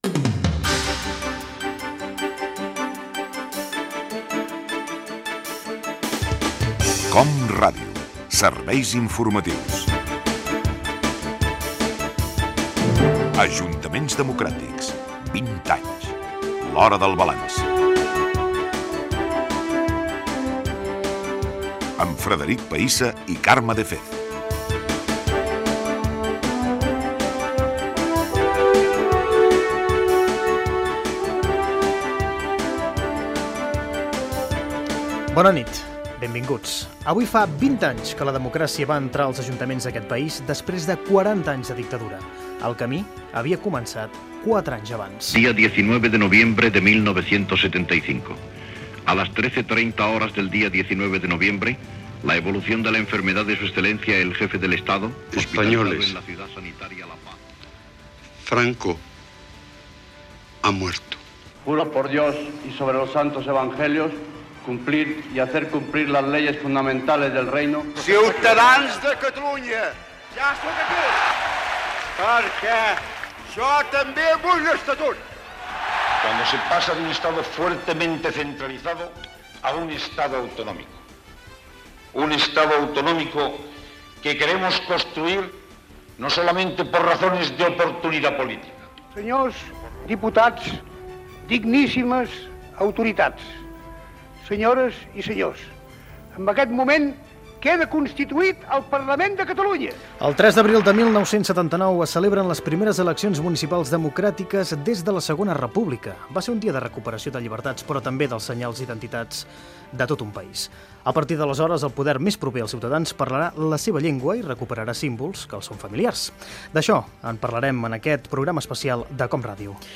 Careta del programa. Presentació: 20 anys de la constitució dels primers ajuntaments democràtics després de la dictadura. Fragments de discursos històrics
Informatiu